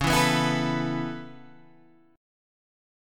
C# Minor 7th Sharp 5th